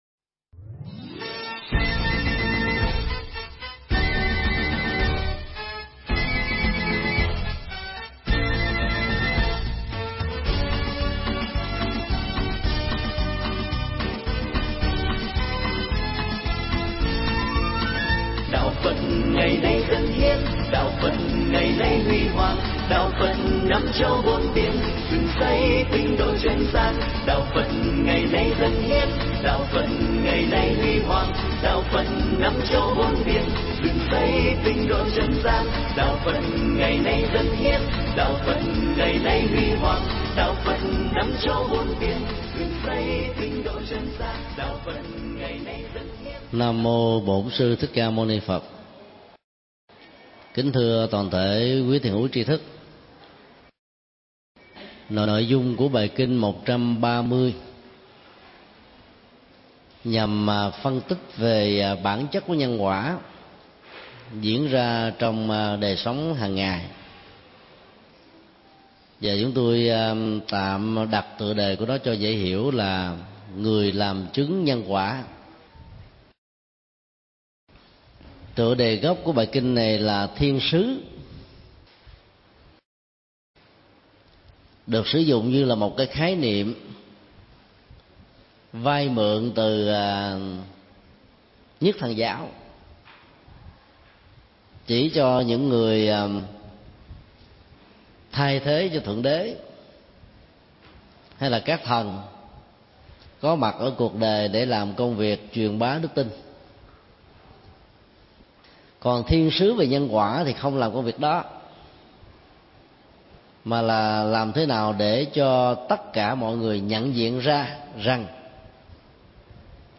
Mp3 Pháp thoại Kinh Trung Bộ 130
Chùa Xá Lợi